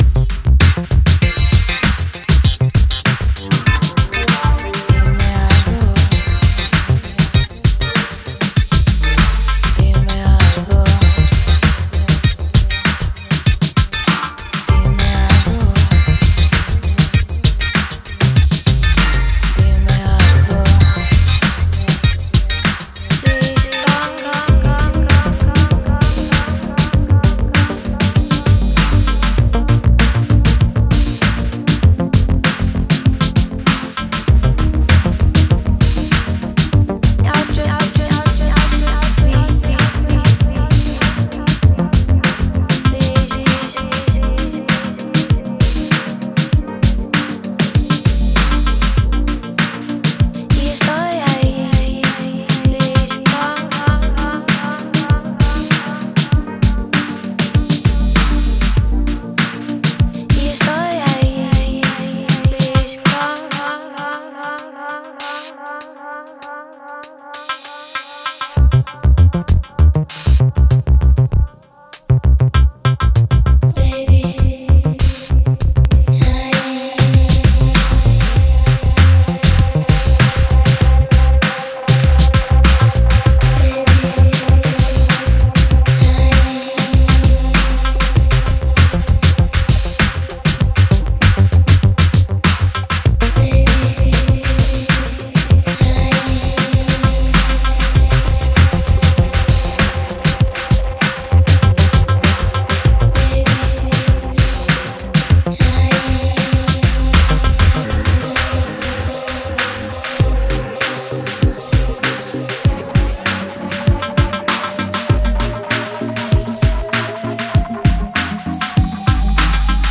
Dub Mix